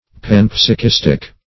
panpsychistic - definition of panpsychistic - synonyms, pronunciation, spelling from Free Dictionary
Pan*psy"chist, n. -- Pan`psy*chis"tic, a.